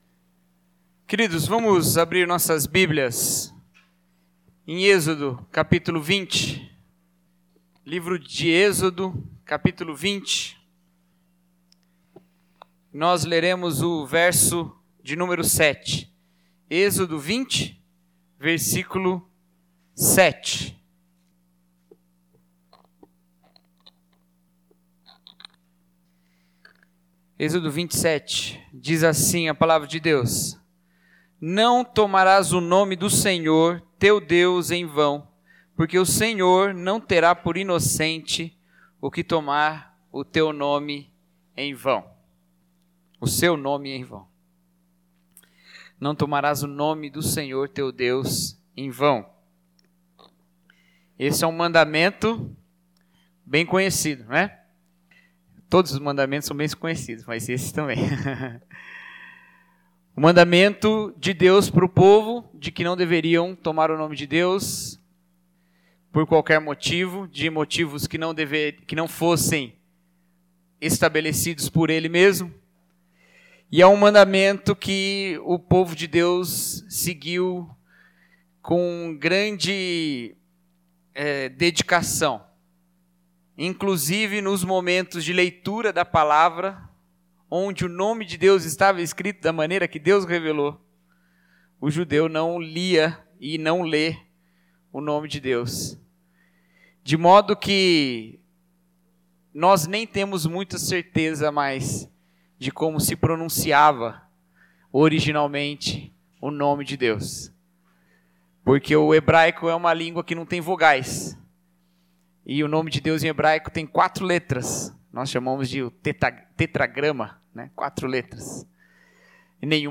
Mensagem: O Nome Que Carregamos